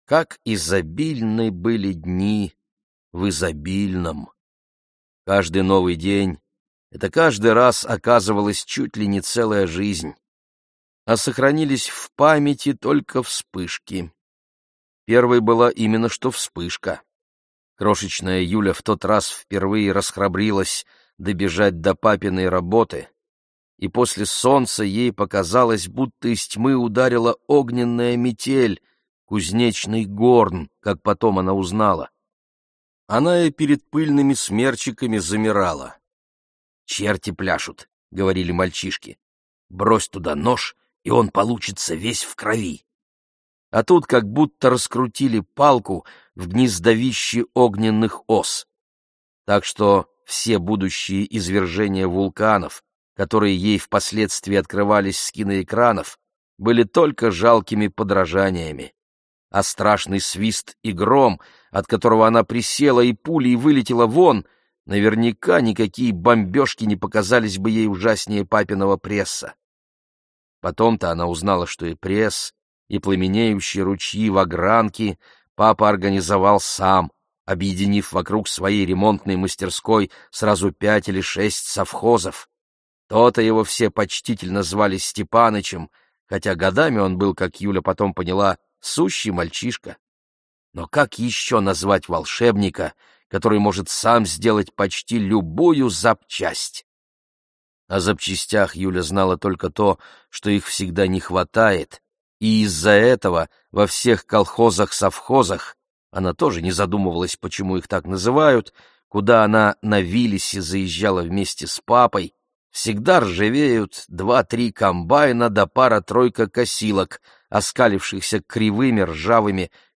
Аудиокнига Свидание с Квазимодо | Библиотека аудиокниг